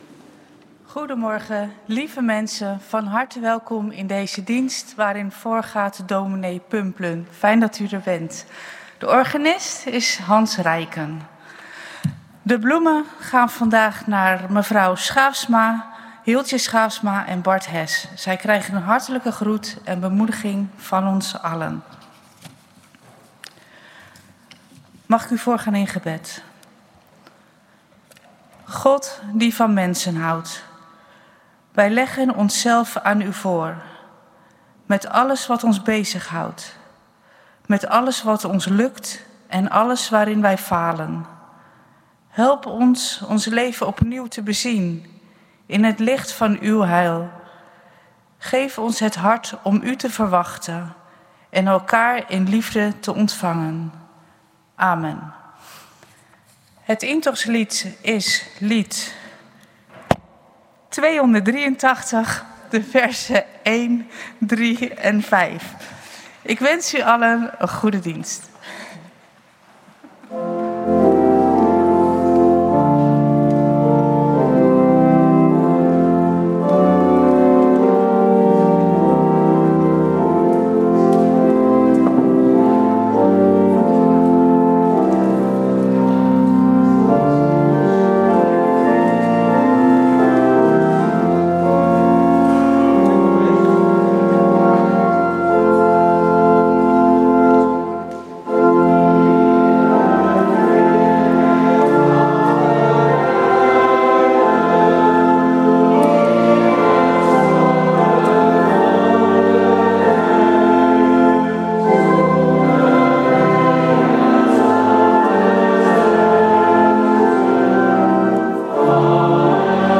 Kerkdiensten - Protestantse Gemeente Oostzaan - Zondag 10.00 uur Kerkdienst in de Grote Kerk!
Kerkdienst geluidsopname